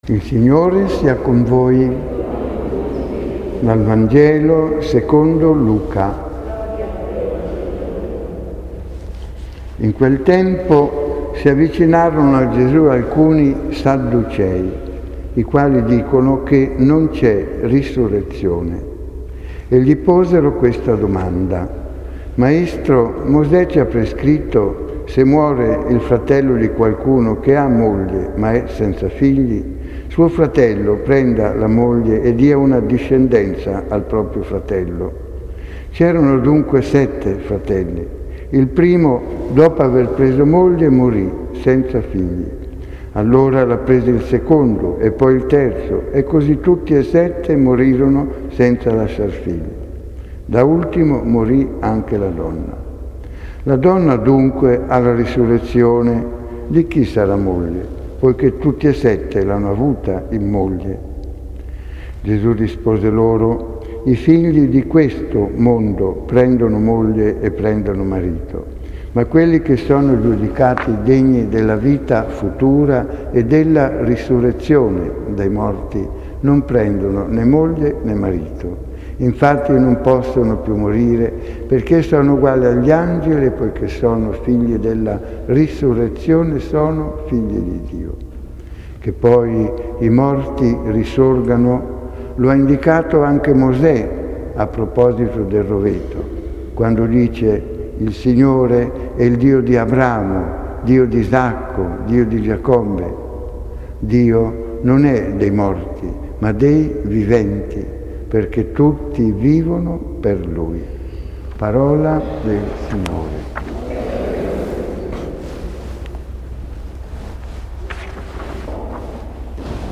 l'omelia